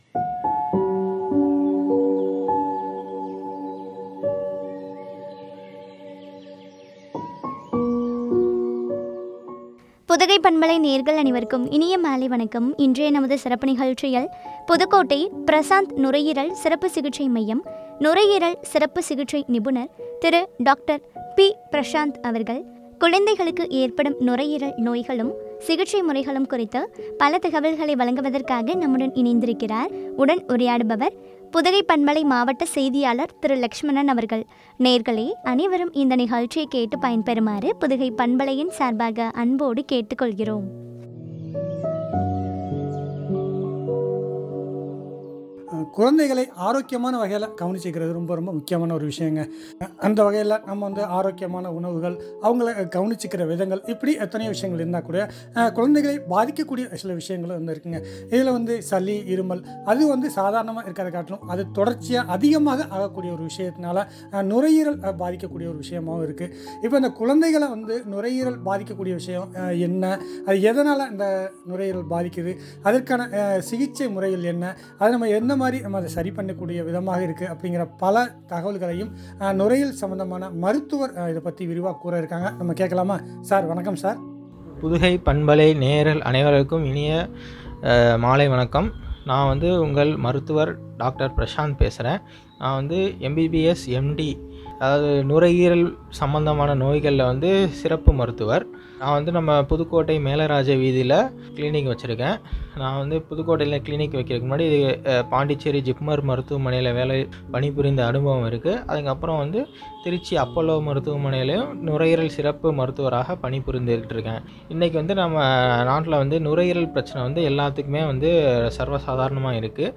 சிகிச்சை முறைகளும் பற்றிய உரையாடல்.